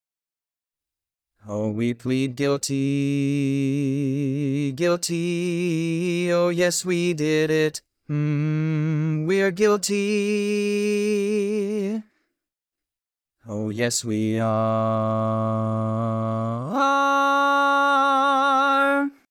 Key written in: D♭ Major
Type: Barbershop
Each recording below is single part only.